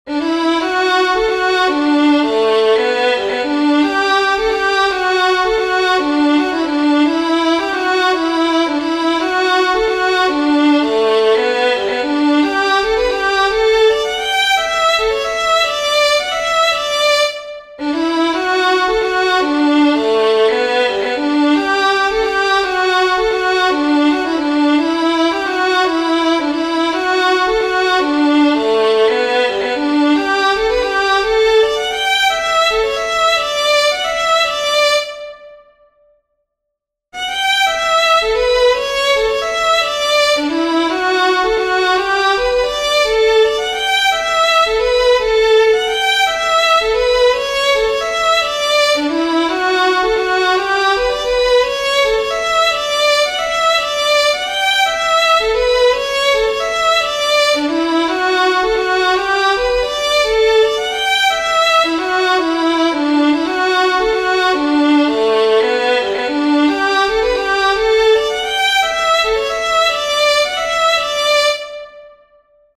a lovely march